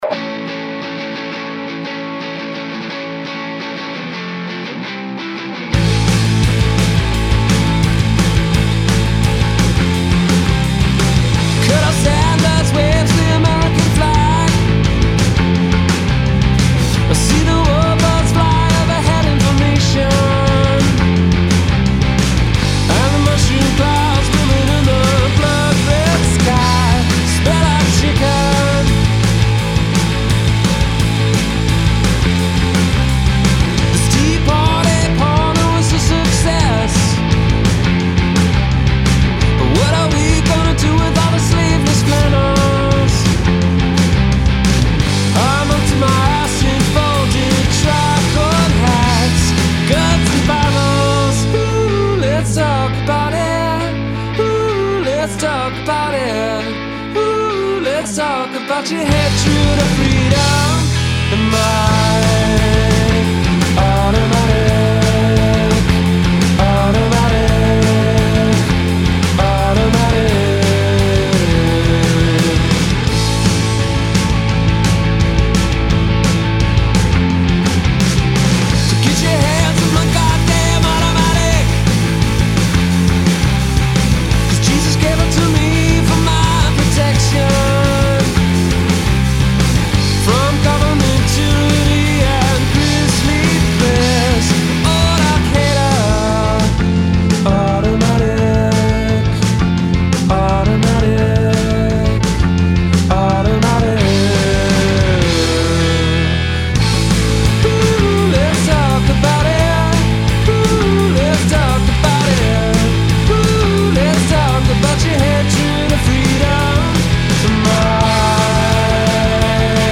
guitarist
rolling guitar riffs create a break neck pace